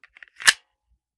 9mm Micro Pistol - Loading Magazine 003.wav